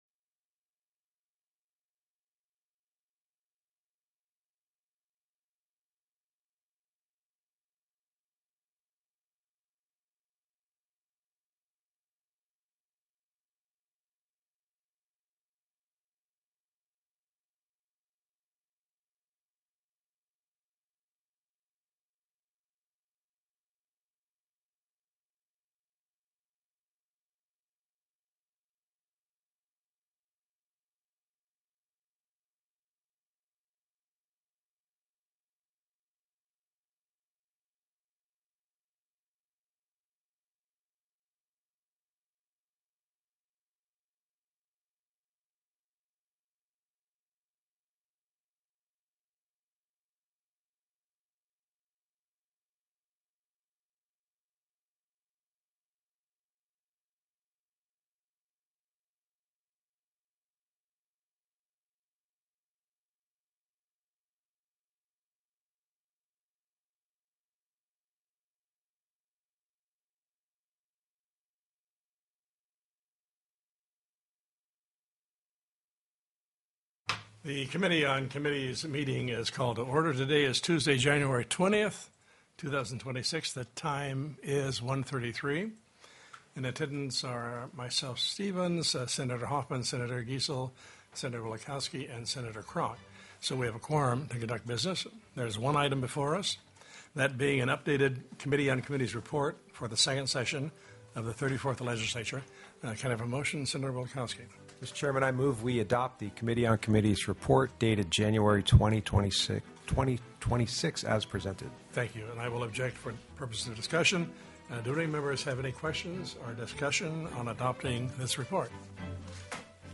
01/20/2026 01:30 PM Senate COMMITTEE ON COMMITTEES
The audio recordings are captured by our records offices as the official record of the meeting and will have more accurate timestamps.
Senator Gary Stevens, Chair